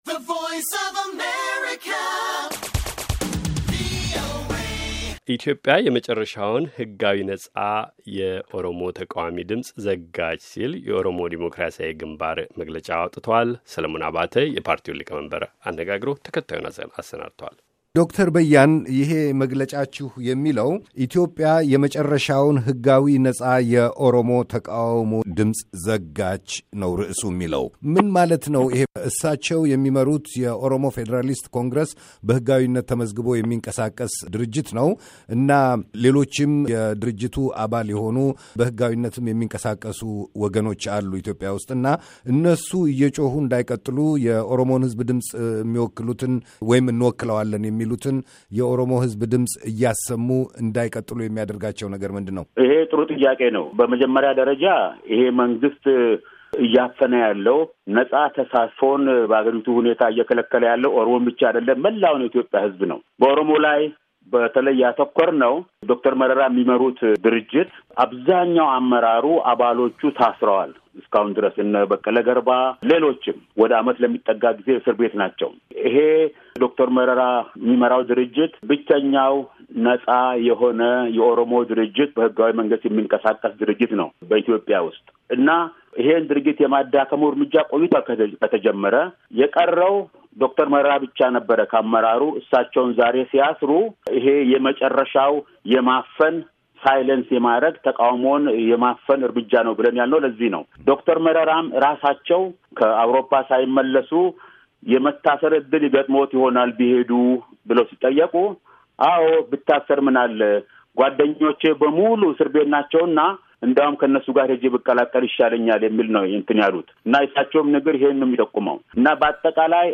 ቃለ መጠይቅ